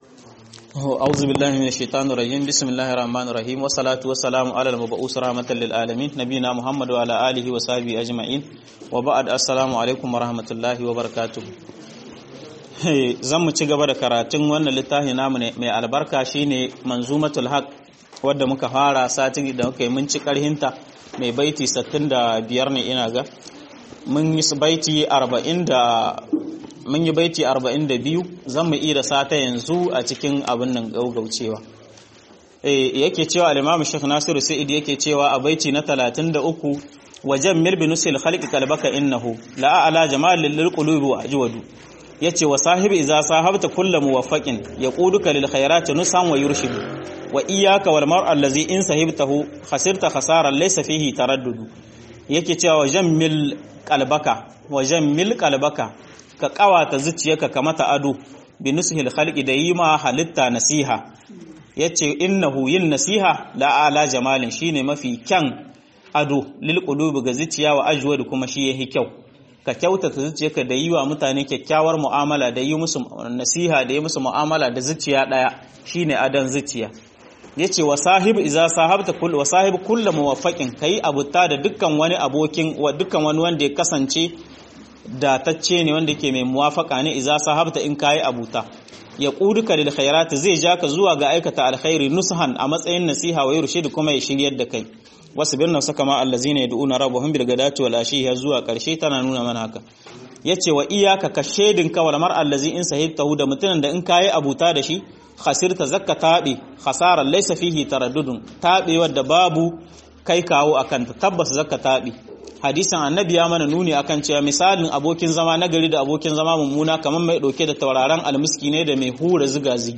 منظومة الحق للسعدي (2) - MUHADARA